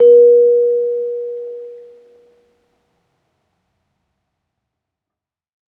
kalimba1_circleskin-B3-pp.wav